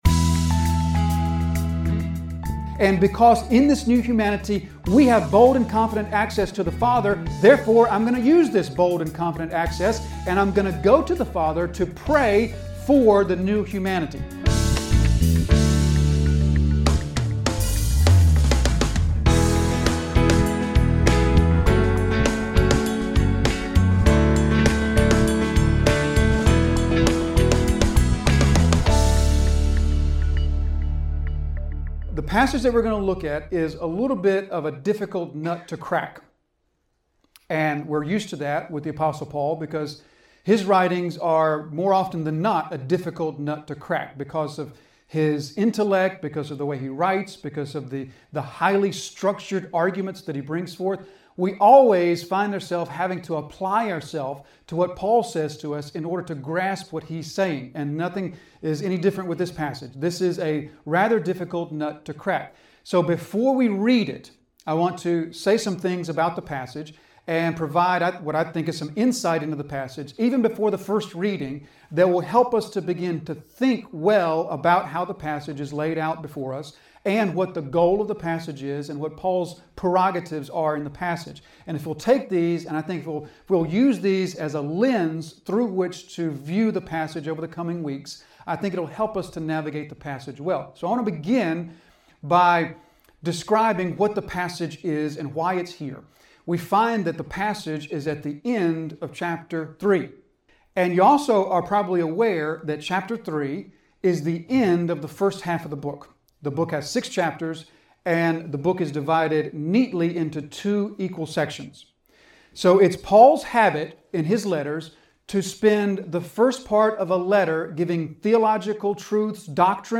An expository sermon delivered at Disciples Fellowship Church, Jonesville, NC.